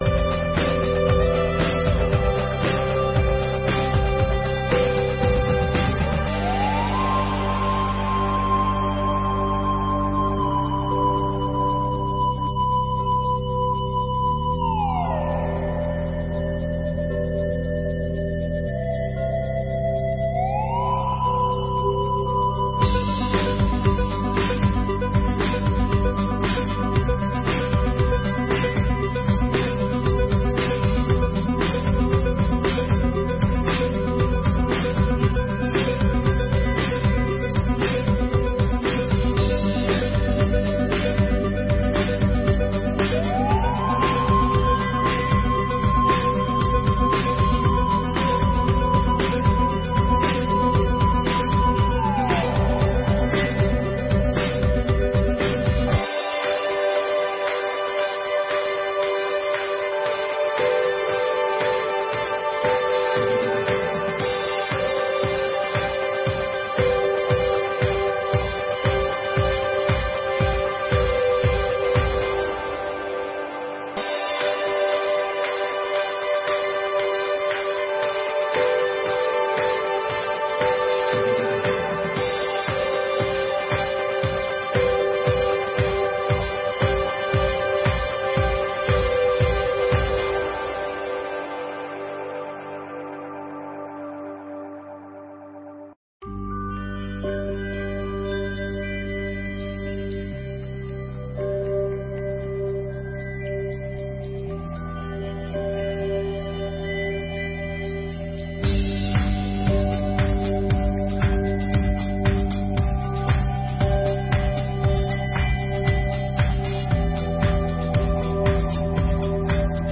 Audiências Públicas de 2022